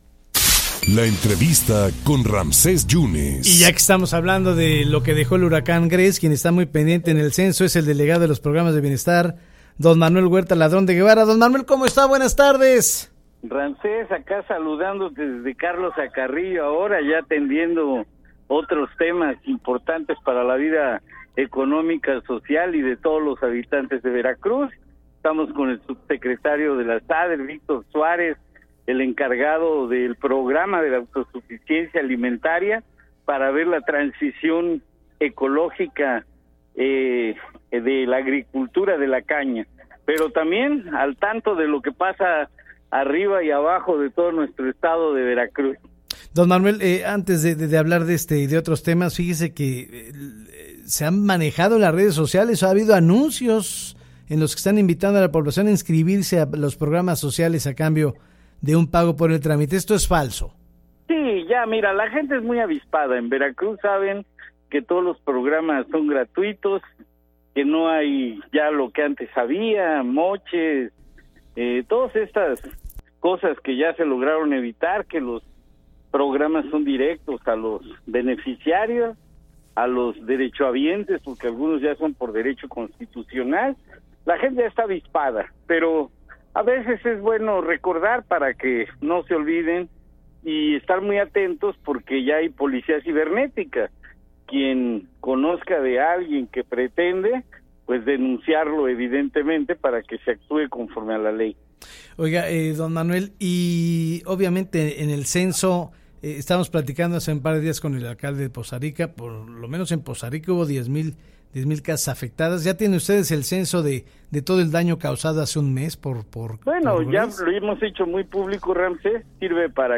El delegado de los programas del bienestar, Manuel Huerta Ladrón de Guevara, comentó en entrevista acerca de la falses de las cuotas para inscribirse en los programas de ayuda a los beneficiarios, y que la gente ya no se deja engañar al respecto.